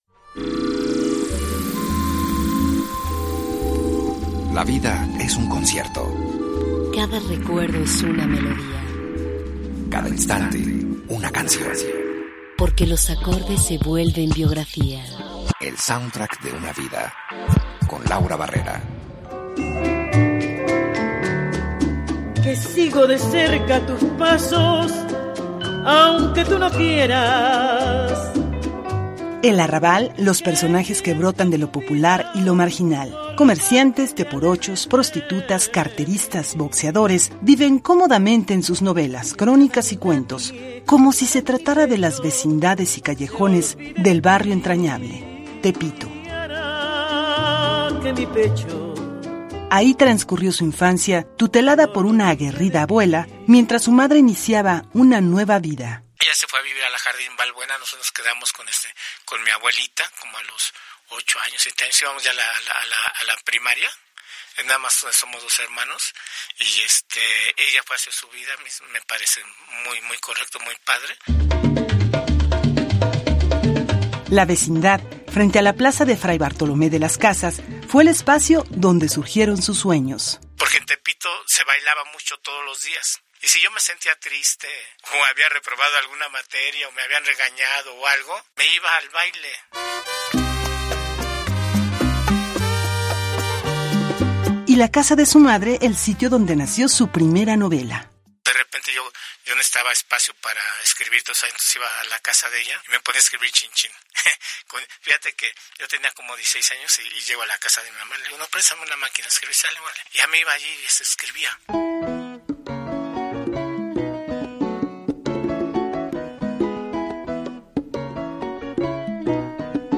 Escucha a Armando Ramírez entrevistado